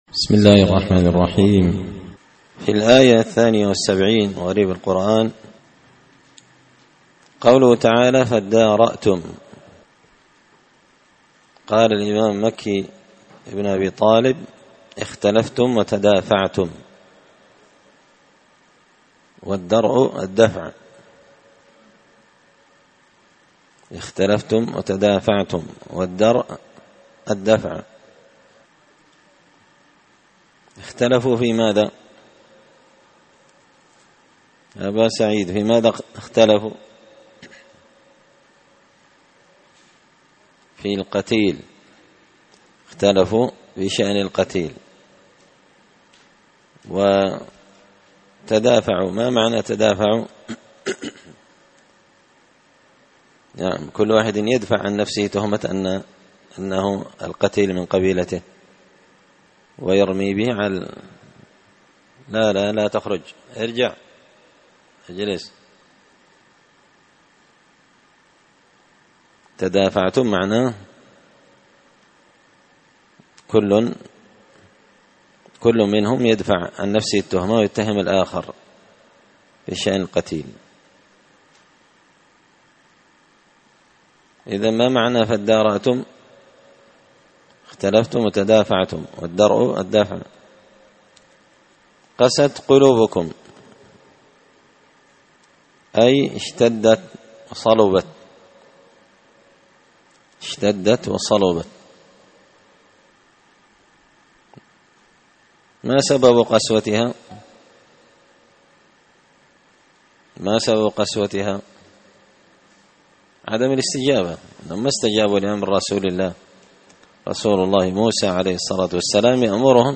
تفسير مشكل غريب القرآن ـ الدرس 15
دار الحديث بمسجد الفرقان ـ قشن ـ المهرة ـ اليمن